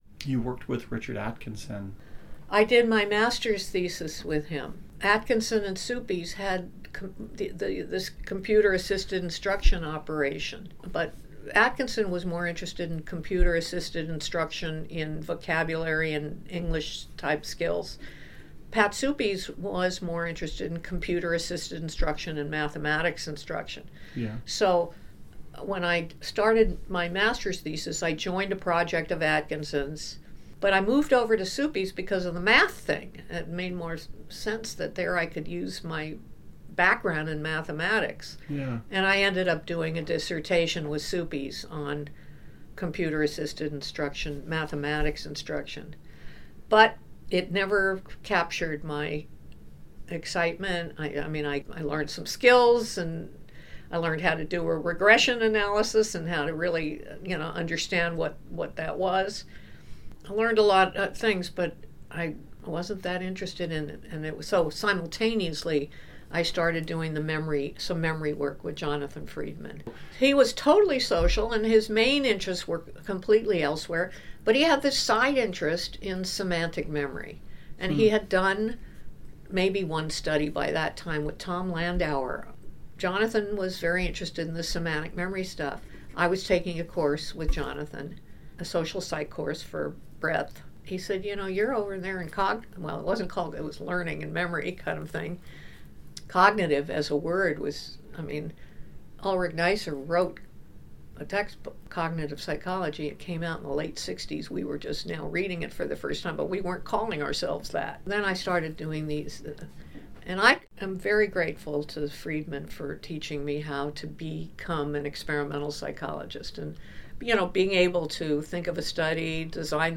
In this next excerpt, Dr. Loftus describes how she came to thinking about Psychology and Law and finding the trajectory of her scholarship thereafter: